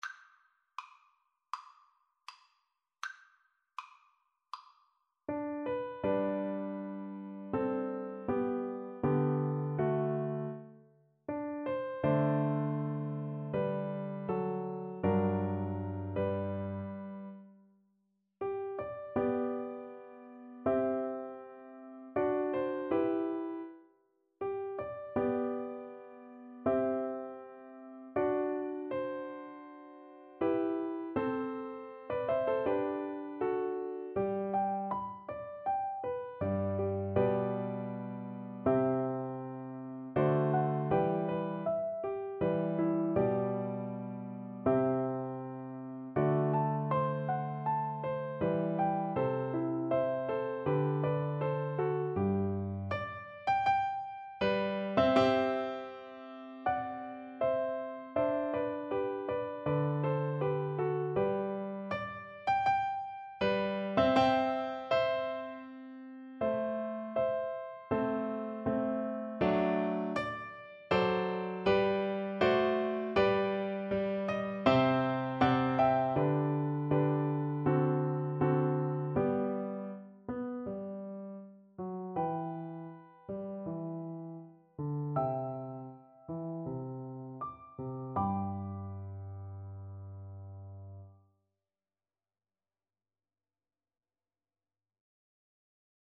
Play (or use space bar on your keyboard) Pause Music Playalong - Piano Accompaniment Playalong Band Accompaniment not yet available transpose reset tempo print settings full screen
Andante
G major (Sounding Pitch) (View more G major Music for Flute )
Classical (View more Classical Flute Music)